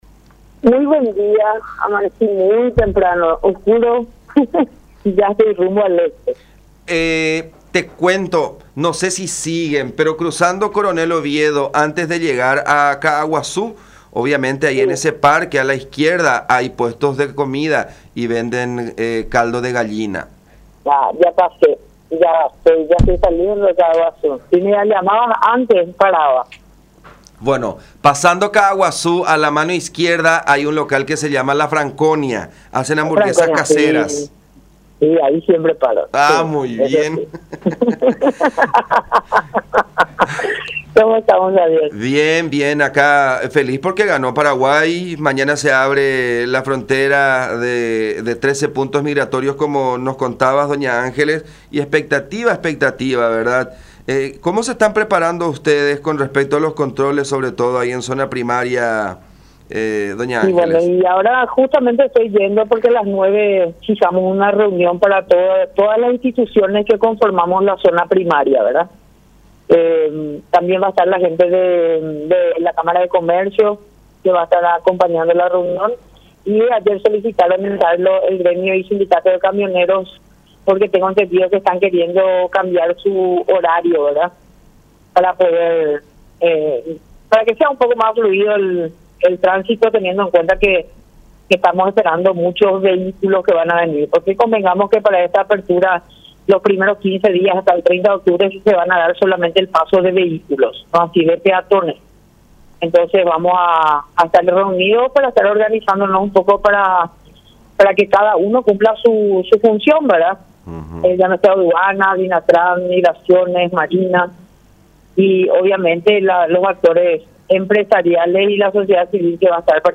“Tenemos hoy una reunión con las instituciones que conformamos la zona primaria y la gente de la Cámara de Comercio de Ciudad del Este, así como representantes del sindicato de camioneros, que reclaman un tránsito más fluido”, dijo Arriola en conversación con La Unión.